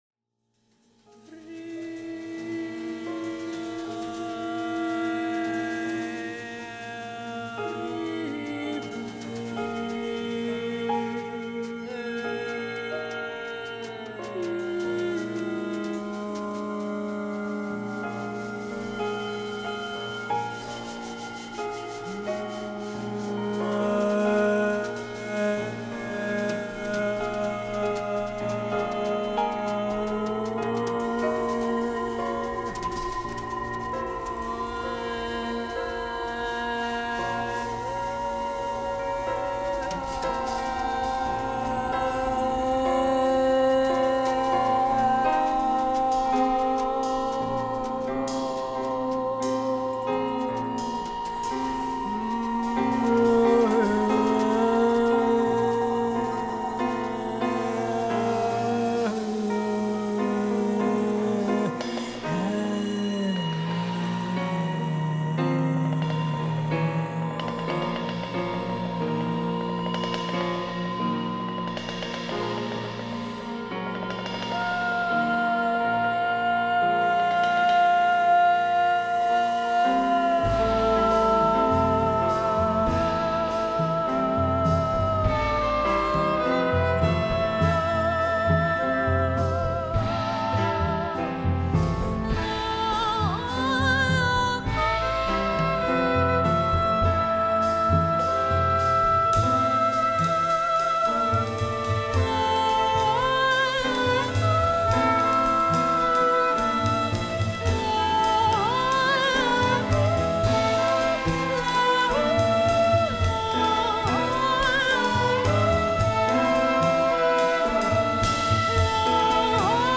aus der jährlichen Konzertreihe